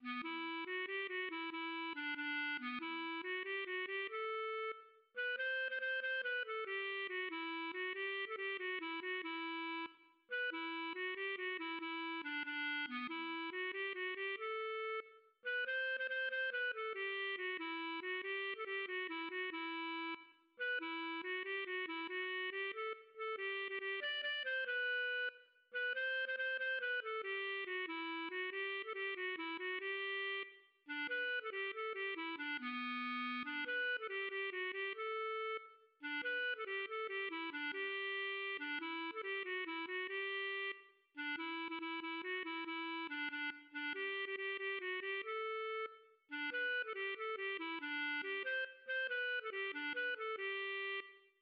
LilyPond 🏰" } myMusic = { << \chords { \germanChords \set chordChanges=##t \set Staff.midiInstrument="acoustic guitar (nylon)" | | | | } \relative c' { \time 4/4 \key g \major \time 6/8 \tempo 4=140 \partial 8 \set Staff.midiInstrument="clarinet" b8 \repeat volta 2 { e4 fis8 g8 fis8 e8